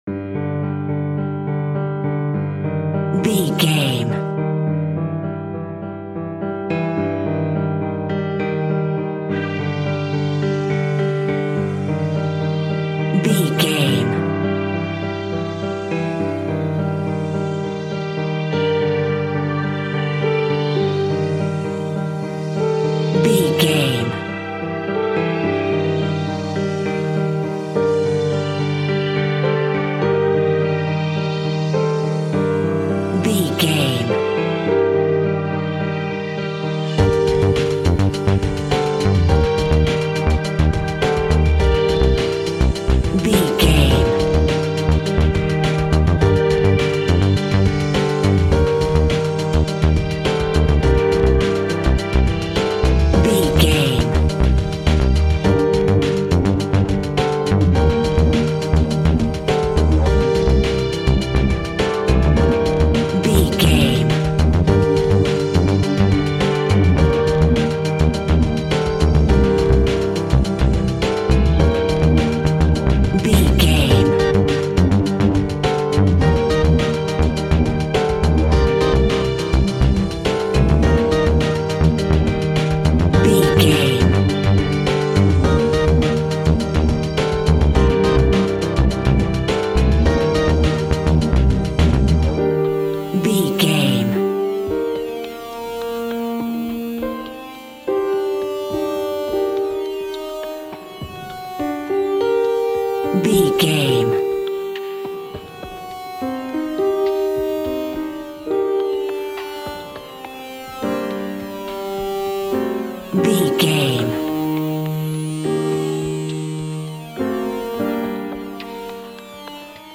Aeolian/Minor
Fast
tension
ominous
dramatic
eerie
piano
synthesiser
drums
ambience
pads